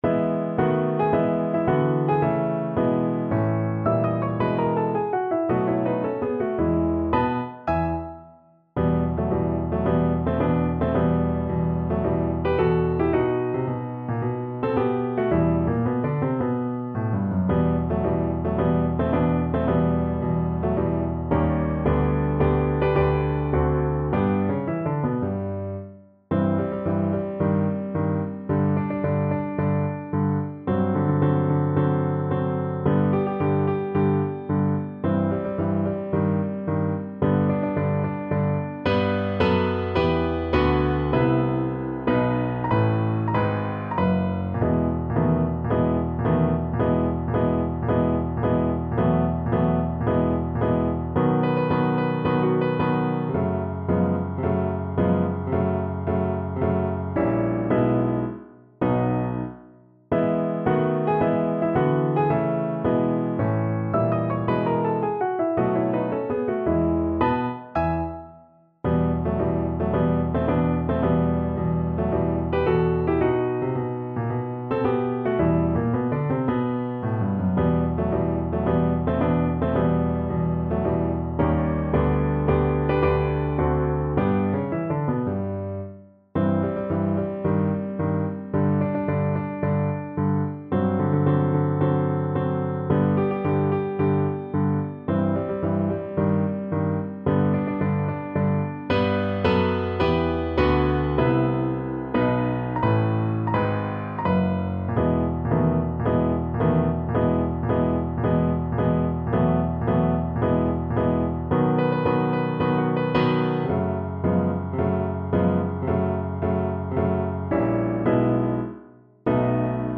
2/4 (View more 2/4 Music)
~ = 110 Marziale
Classical (View more Classical Voice Music)